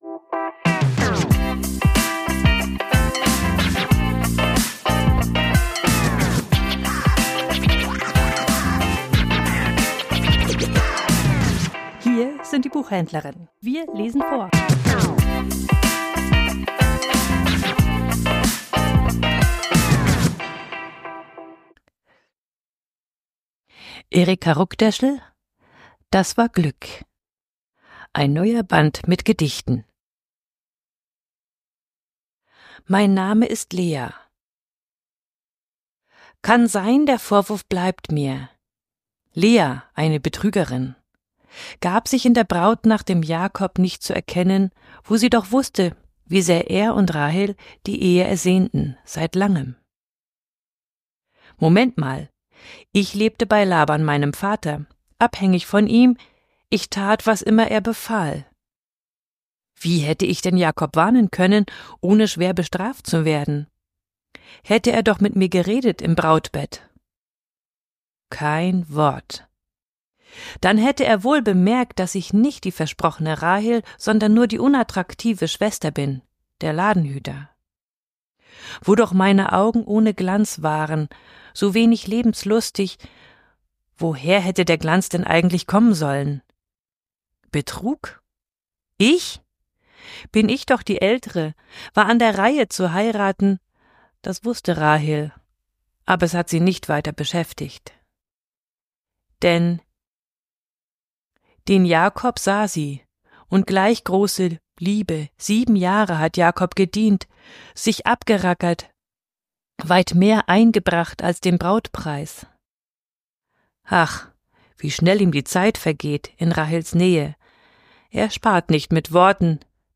Vorgelesen: Das war Glück